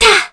Kara-Vox_Jump_kr.wav